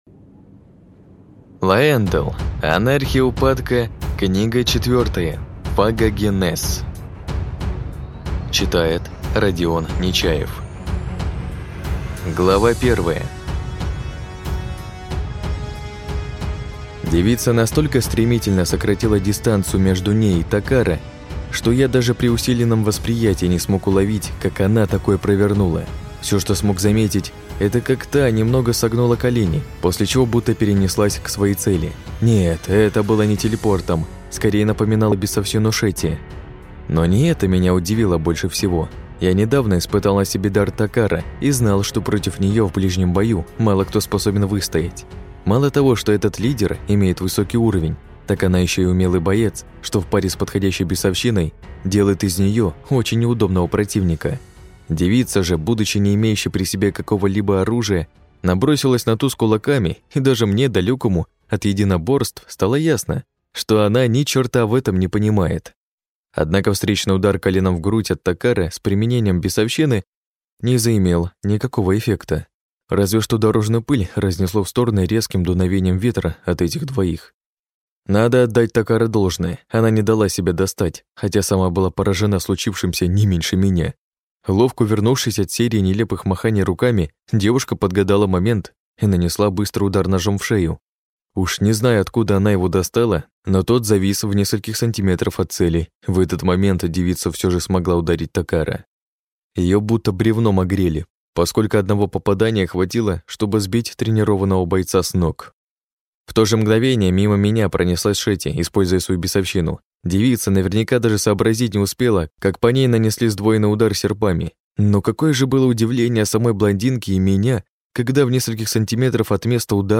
Аудиокнига Фагогенез | Библиотека аудиокниг